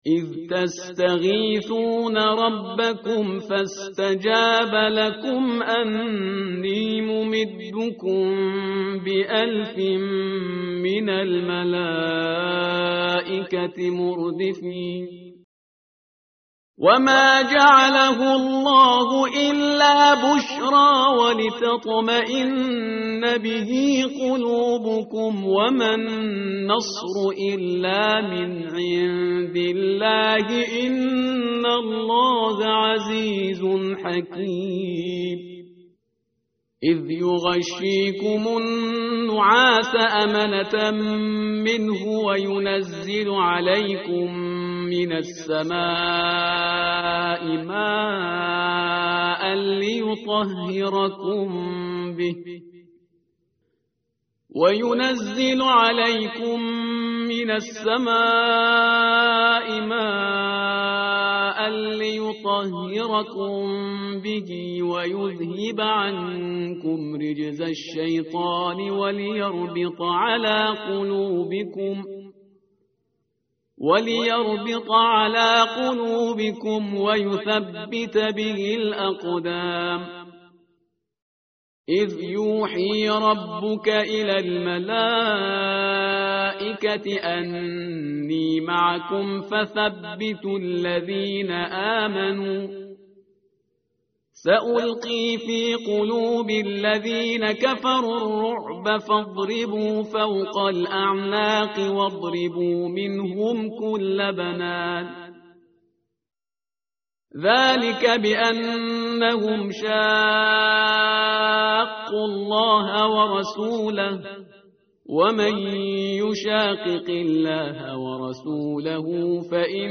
متن قرآن همراه باتلاوت قرآن و ترجمه